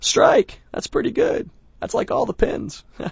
gutterball-3/Gutterball 3/Commentators/Bill/b_strike_3.wav at 608509ccbb5e37c140252d40dfd8be281a70f917